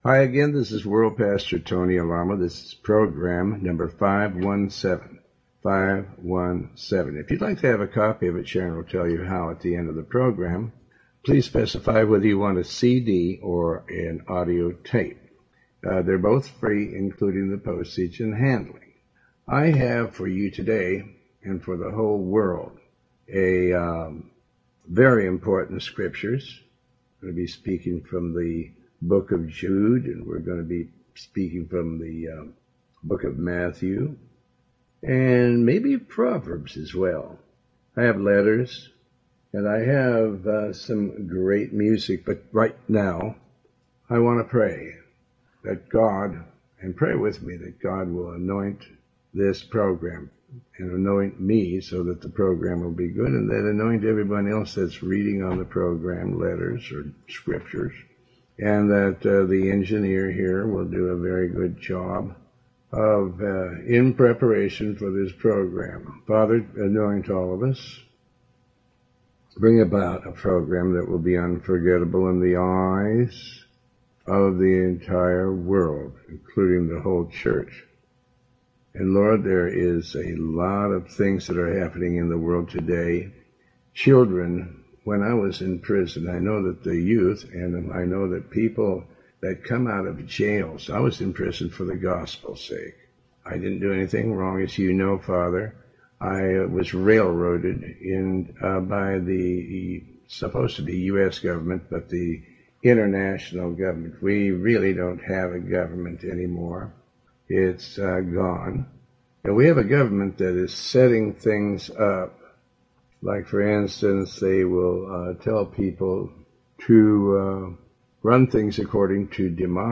Talk Show Episode, Audio Podcast, Tony Alamo and Program 517 on , show guests , about Tony Alamo Christian Ministries,tony alamo,Faith, categorized as Health & Lifestyle,History,Love & Relationships,Philosophy,Psychology,Christianity,Inspirational,Motivational,Society and Culture